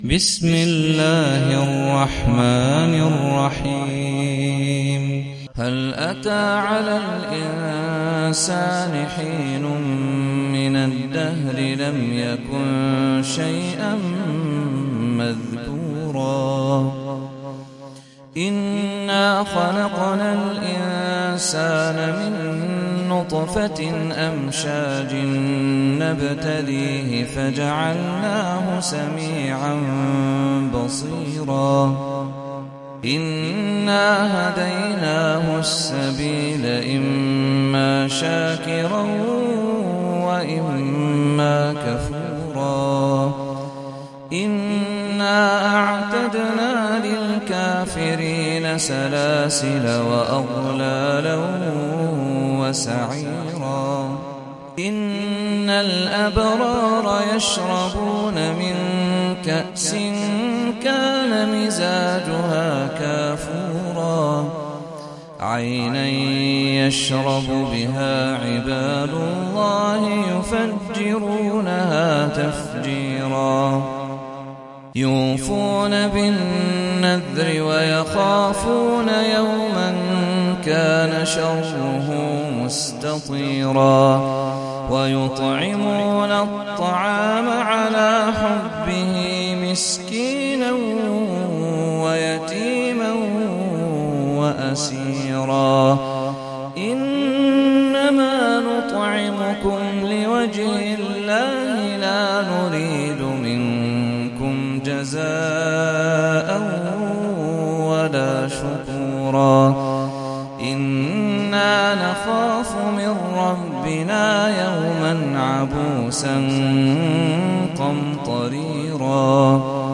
سورة الإنسان - صلاة التراويح 1446 هـ (برواية حفص عن عاصم)